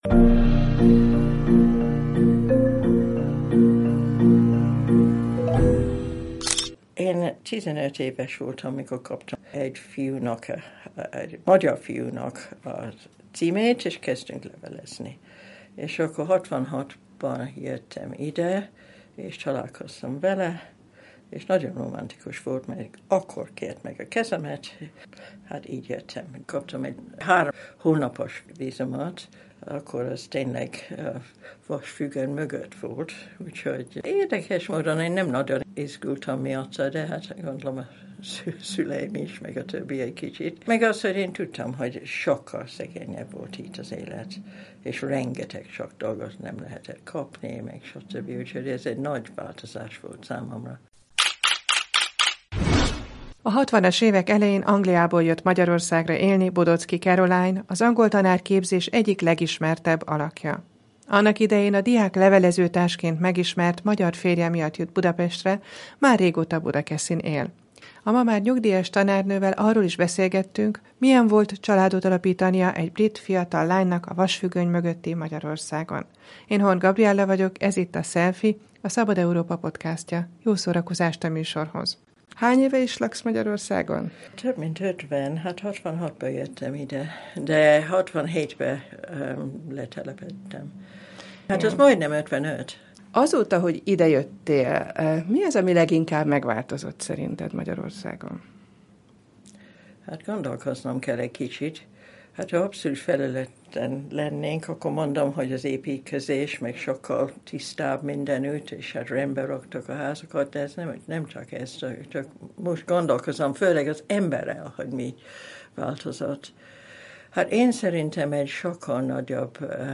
A tanárnővel arról is beszélgettünk, milyen volt családot alapítania egy fiatal brit lánynak a vasfüggöny mögötti Magyarországon.